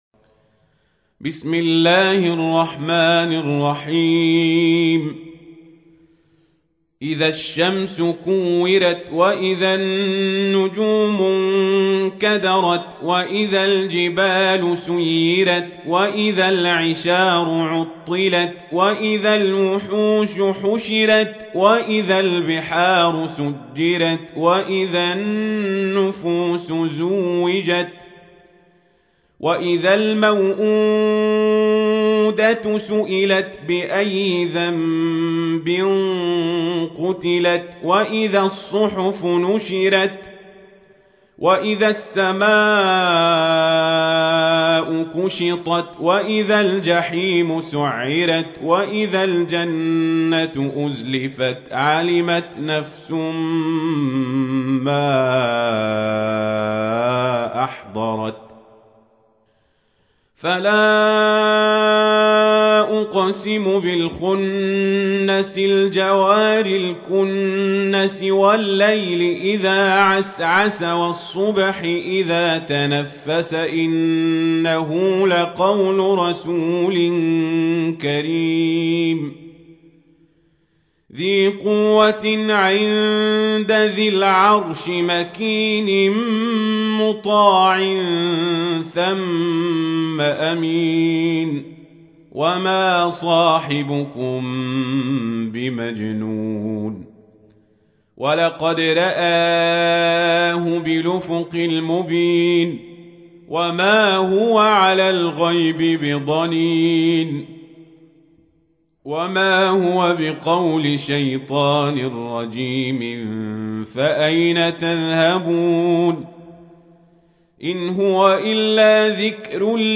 تحميل : 81. سورة التكوير / القارئ القزابري / القرآن الكريم / موقع يا حسين